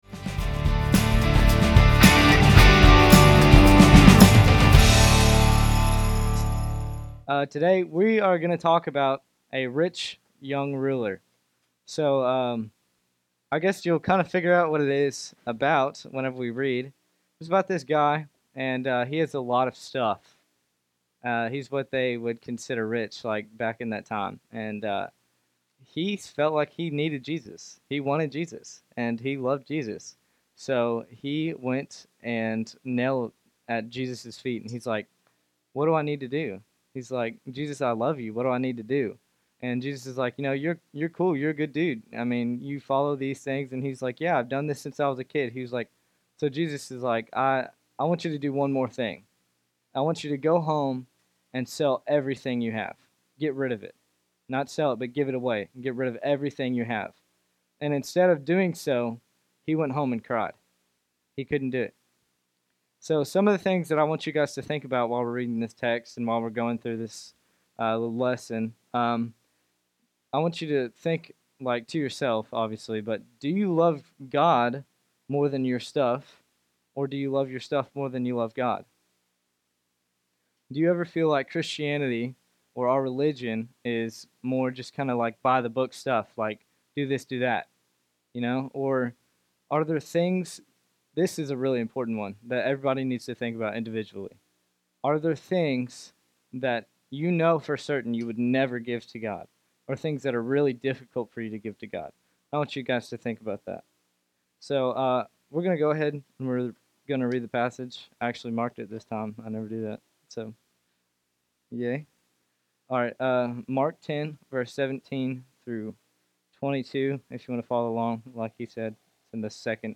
In Mark 10:17-22 a rich young man asks Jesus what he must do to inherit eternal life. Originally preached to Henderson Student Ministry.